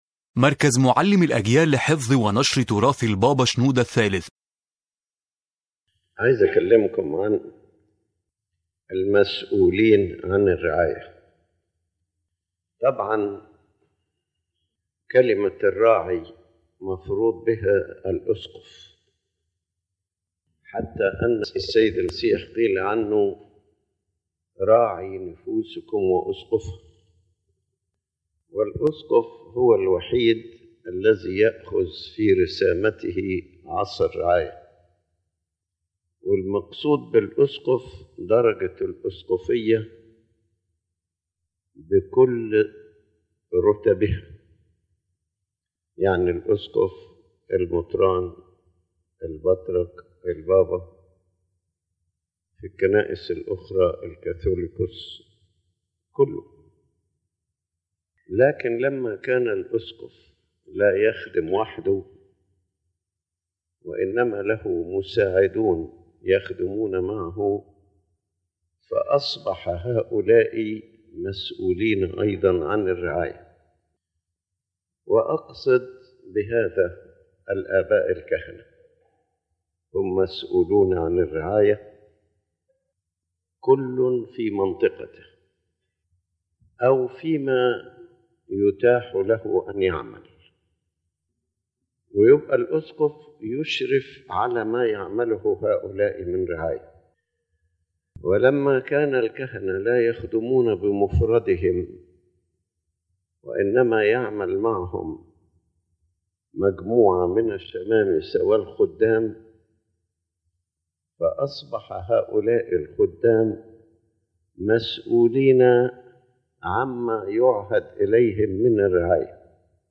His Holiness Pope Shenouda III speaks about the concept of pastoral care in the Church and those responsible for it, explaining that pastoral work is not the responsibility of one person only, but rather a shared service in which the bishop, priests, servants, and all bodies working in the Church and society participate.